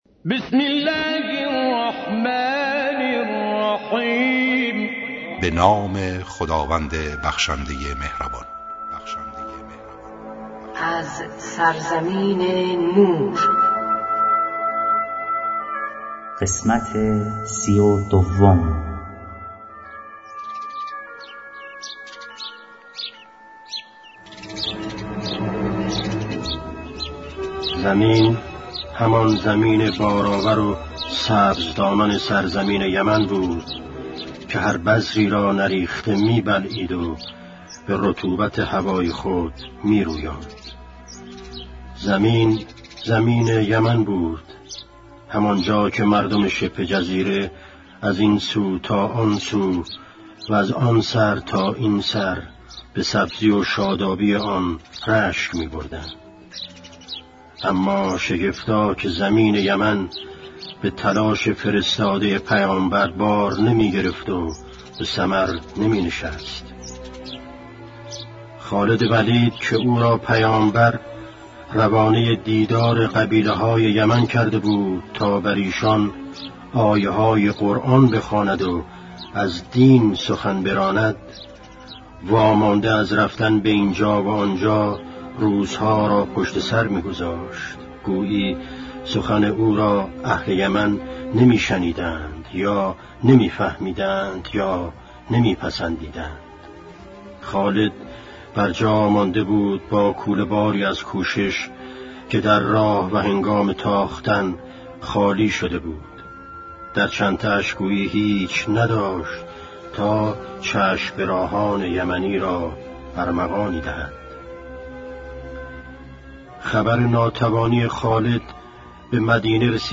تمام قسمت‌های داستان شنیدنی زندگی پیامبر اسلام (ص) و امام علی (ع)؛ با اجرای مشهورترین صداپیشگان، با اصلاح و صداگذاری جدید
کتاب گویا